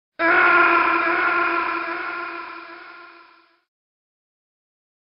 snake_scream.mp3